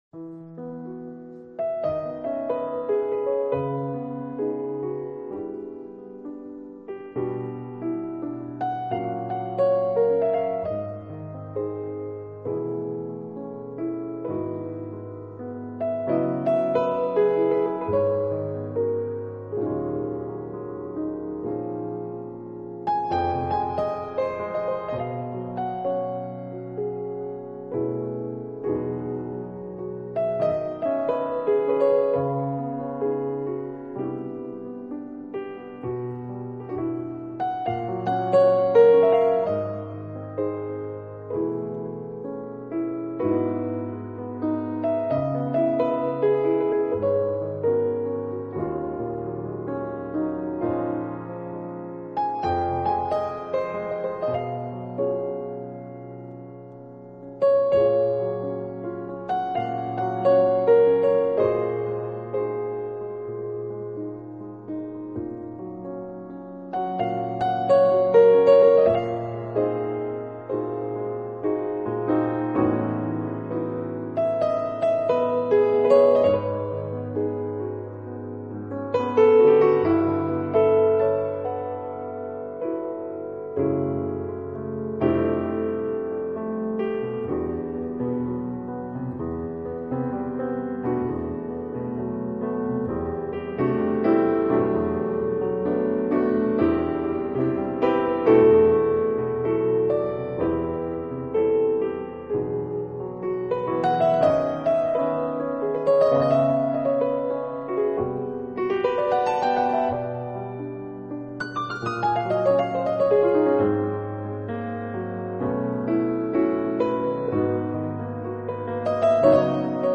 記憶中，爵士總是與杯光酒影、紳士淑女形影相伴，高貴、典雅而慵懶，休閒。